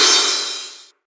VEC3 Crash